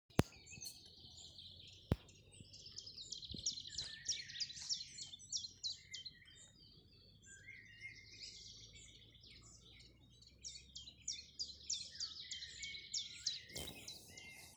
Chiffchaff, Phylloscopus collybita
Ziņotāja saglabāts vietas nosaukumsAlūksnes nov. Opekalns
StatusSinging male in breeding season